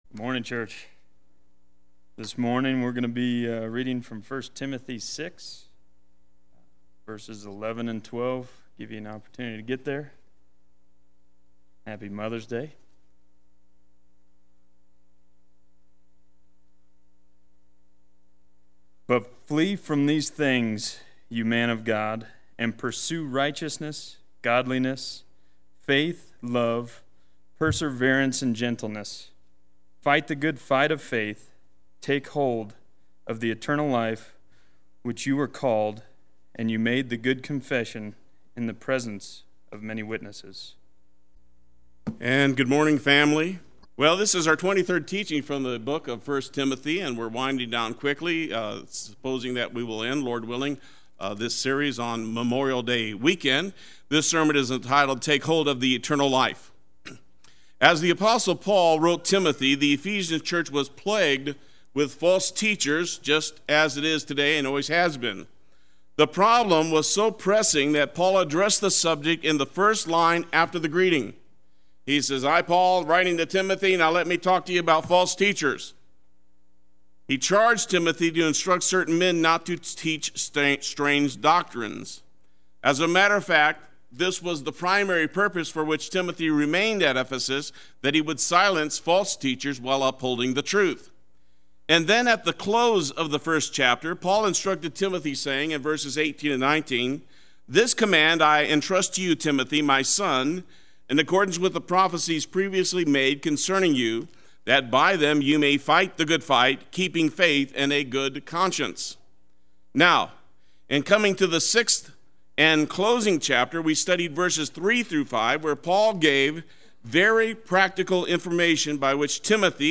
Play Sermon Get HCF Teaching Automatically.
Take Hold of the Eternal Life Sunday Worship